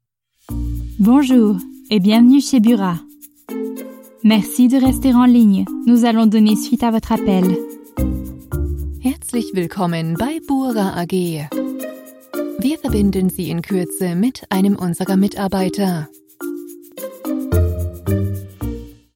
Message d'Accueil Téléphonique
Exemples de messages vocaux:
Nos acteurs voix off réalisent pour vous le message vocal parfait!